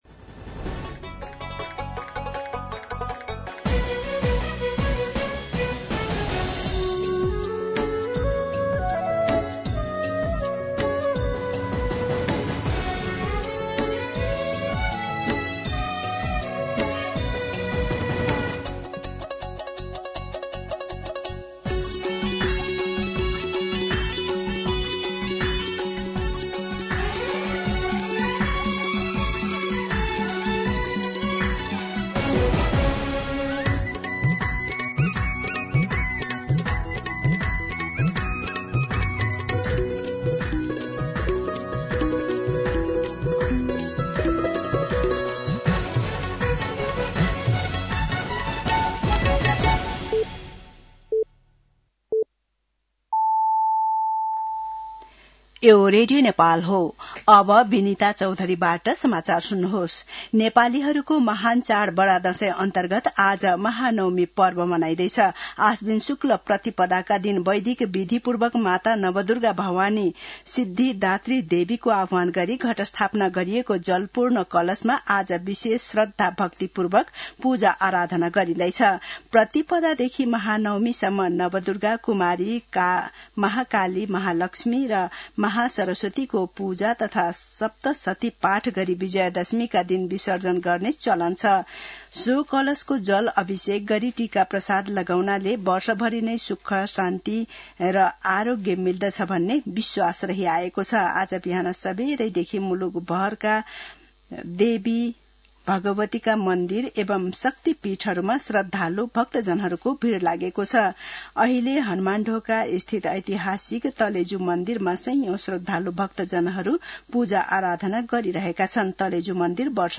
मध्यान्ह १२ बजेको नेपाली समाचार : १५ असोज , २०८२
12pm-News.mp3